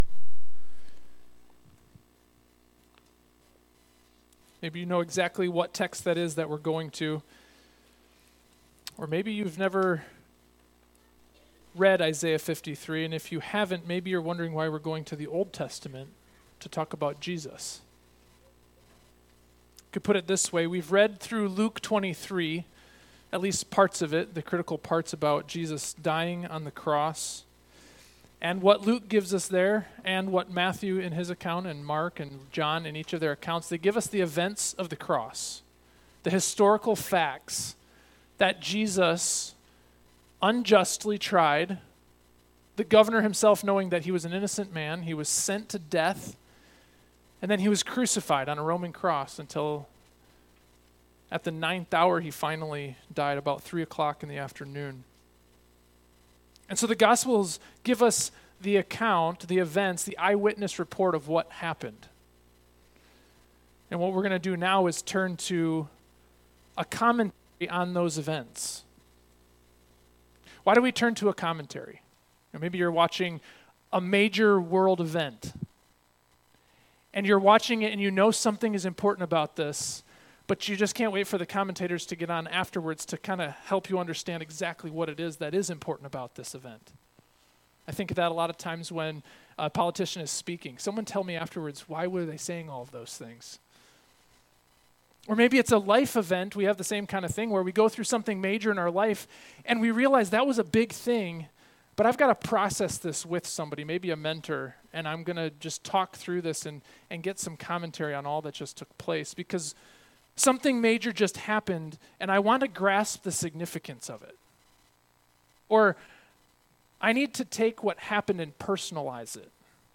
Miscellaneous Sermons
Good Friday